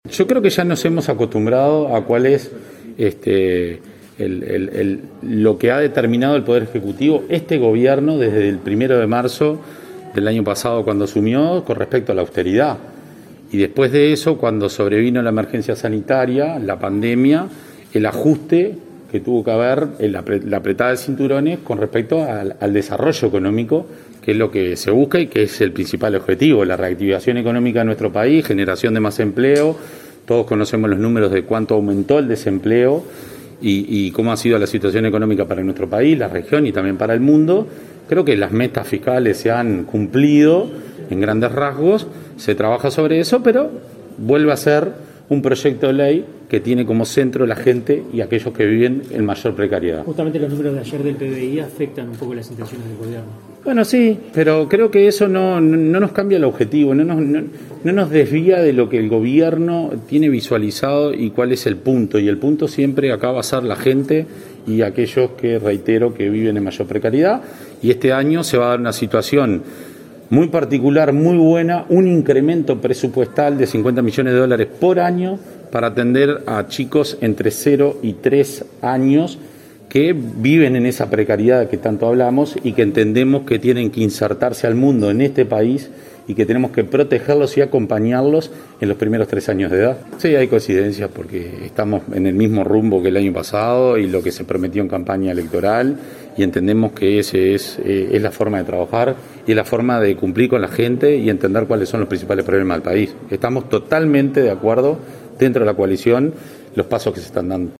El diputado nacionalista Sabastián Andújar, dijo en rueda de prensa que la situación del PBI, «no cambia el objetivo y el punto del gobierno, el cual es la gente, y aquellos que viven en mayor precariedad«.